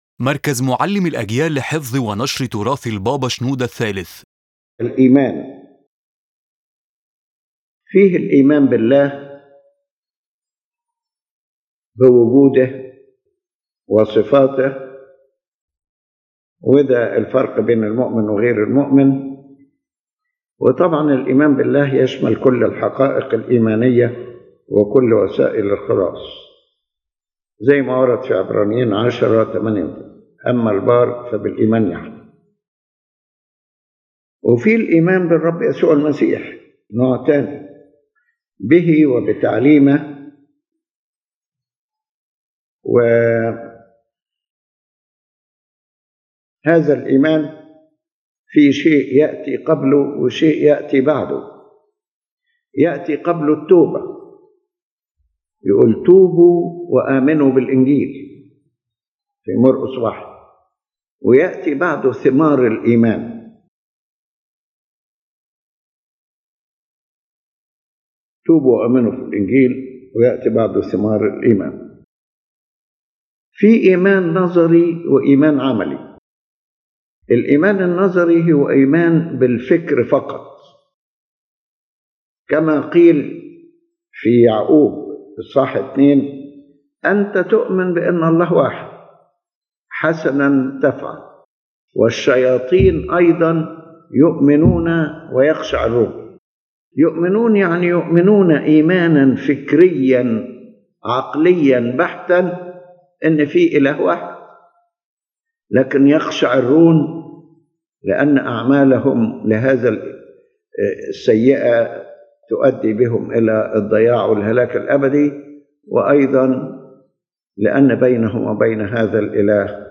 His Holiness Pope Shenouda III addresses in this lecture the meaning of faith and its various dimensions as found in the Holy Bible, explaining that it is the foundation distinguishing the believer from the unbeliever and encompasses all the truths of faith and the means of salvation.